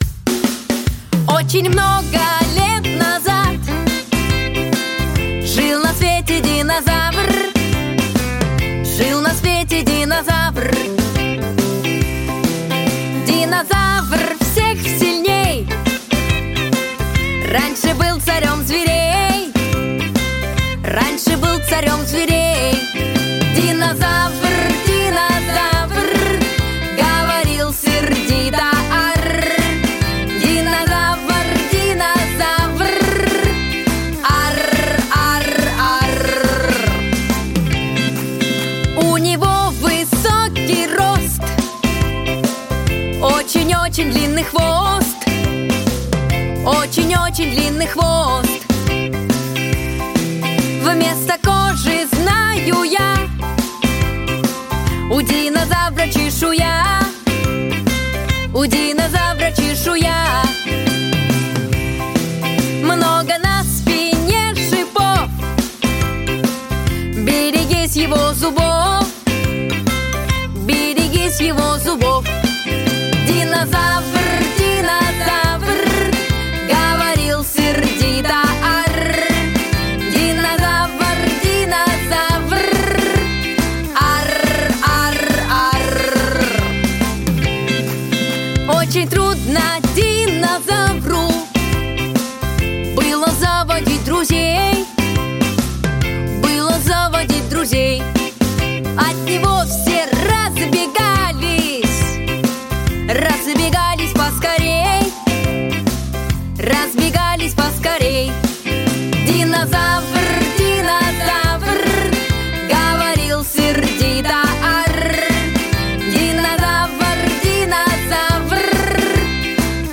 Песни из мультфильмов